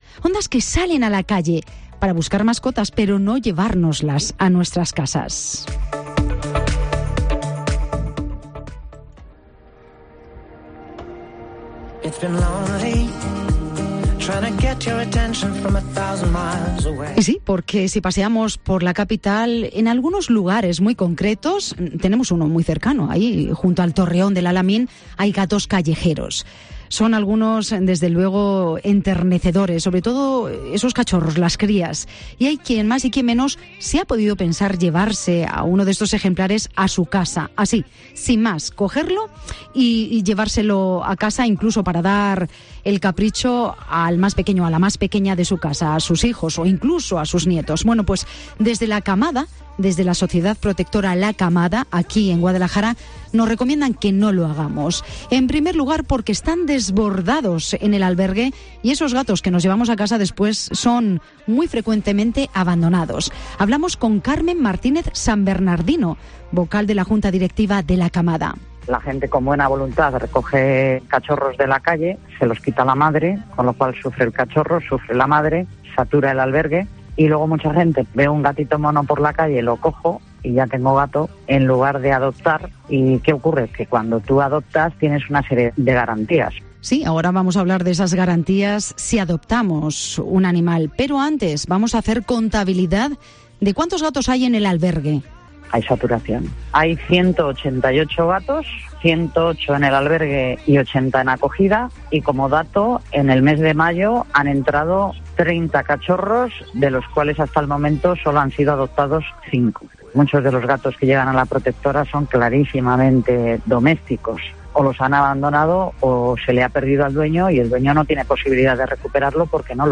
La Asociación Protectora de Animales y Plantas de Guadalajara, La Camada, ha pasado por los micrófonos de 'Herrera en COPE Guadalajara' para denunciar la "saturación" de gatos que sufre el albergue que gestiona en la capital.